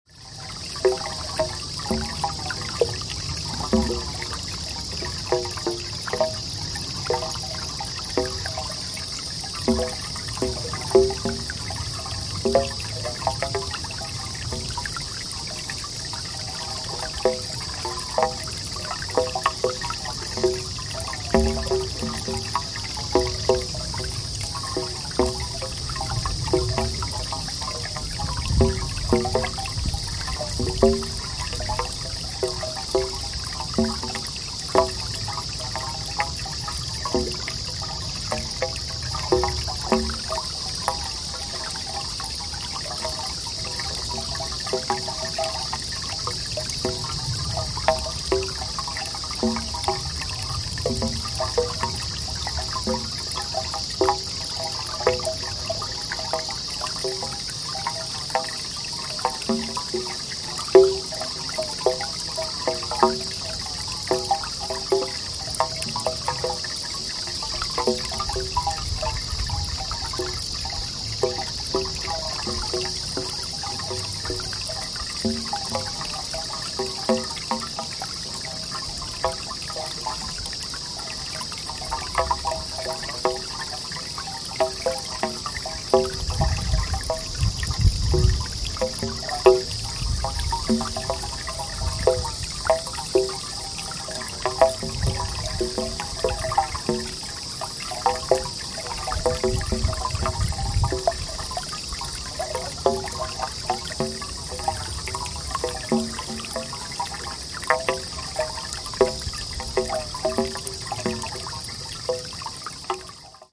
held at Ryosoku-in Temple in Kyoto